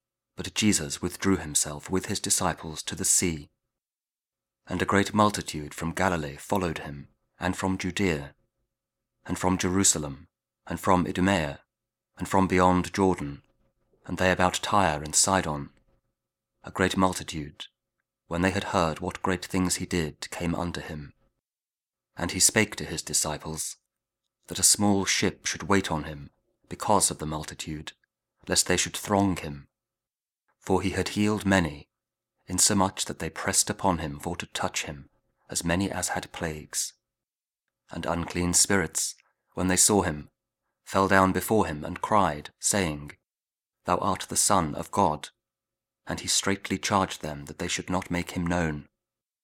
Mark 3: 7-12 – Week 2 Ordinary Time, Thursday (Audio Bible KJV, Spoken Word)